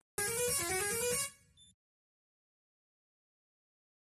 Indian Bus horn Soundboard: Play Instant Sound Effect Button